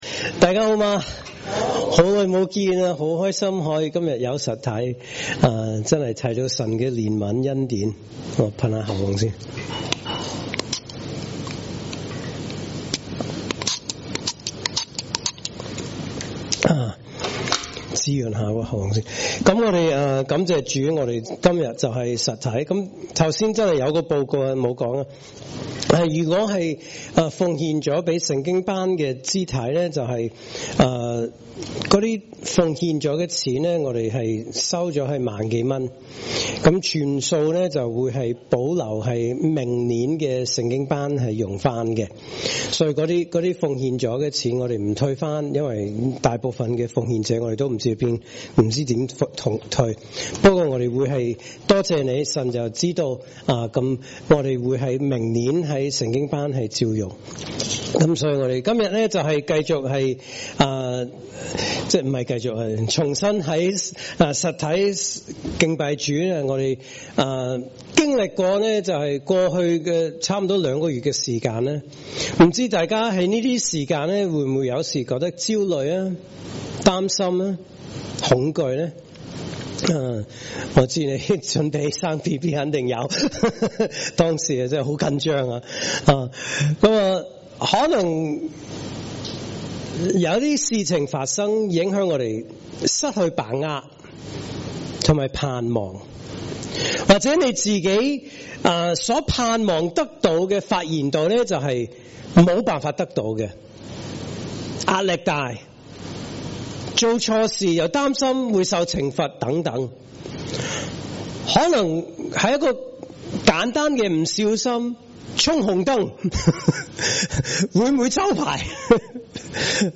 主日崇拜證道系列